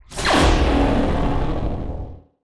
SPACE_WARP_Complex_02_stereo.wav